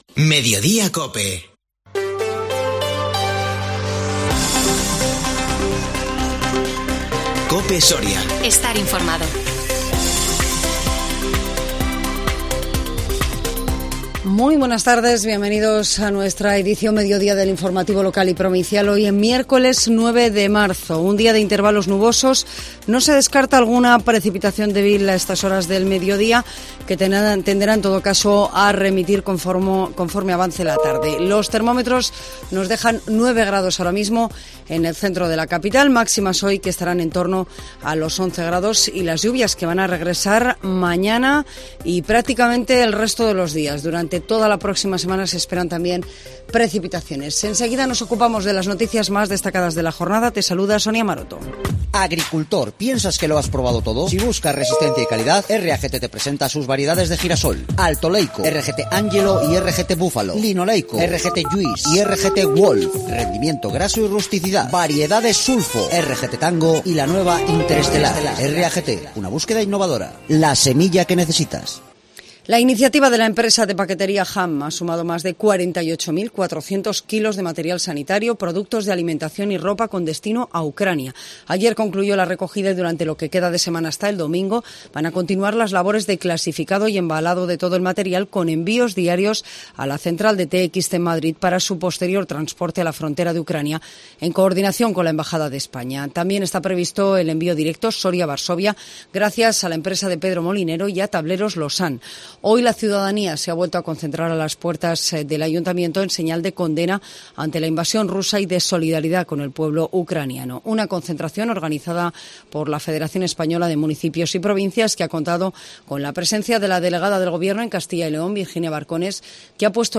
INFORMATIVO MEDIODÍA COPE SORIA 9 MARZO 2022